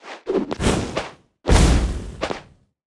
Media:Sfx_Anim_Ultra_Wizard.wav 动作音效 anim 在广场点击初级、经典、高手和顶尖形态或者查看其技能时触发动作的音效
Sfx_Anim_Super_Wizard.wav